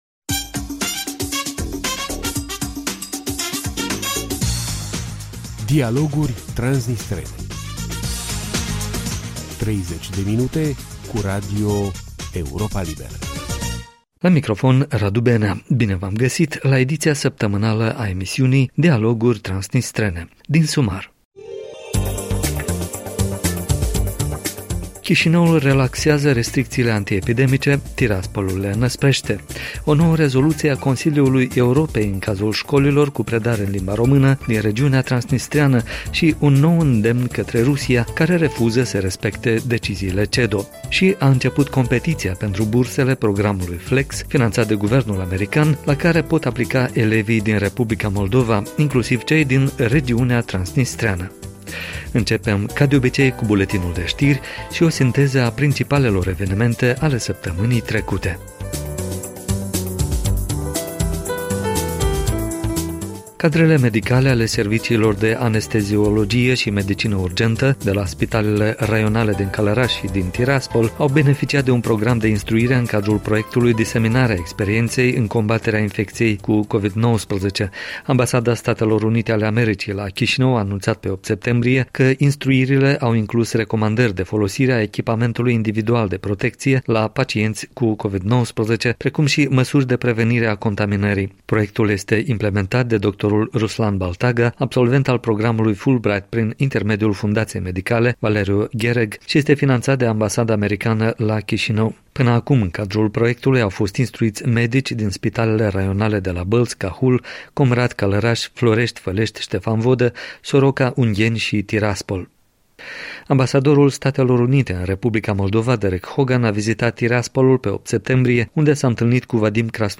Bine v-am găsit la ediția săptămânală a emisiunii Dialoguri transnistrene. Din sumar: Chișinăul relaxează restricțiile antiepidemice, Tiraspolul le înăsprește.